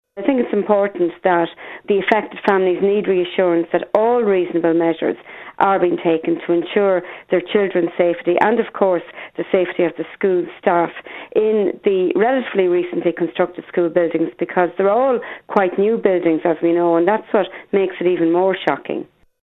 Chair of the Oireachtas Education Committee, Fiona O’Loughlin, says the recent discoveries have caused major disruption for children and their families: